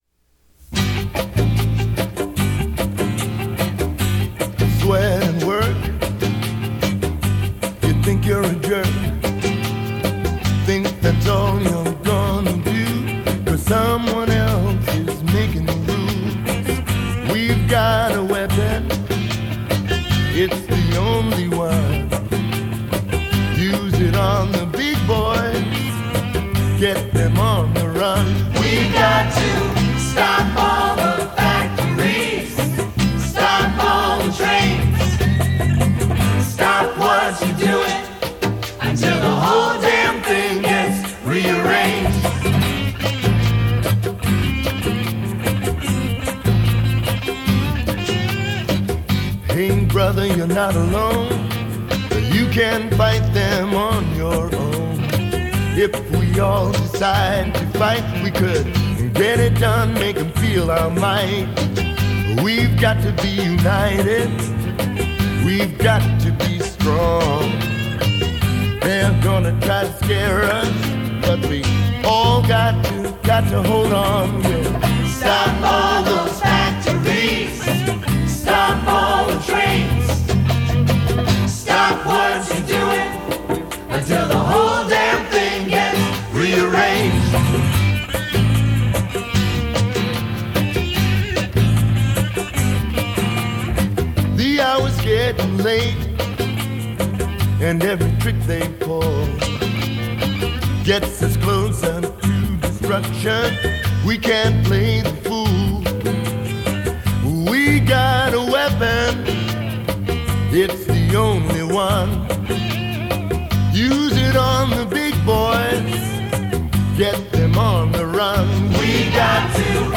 guest vocal
Sung by the writers except as noted.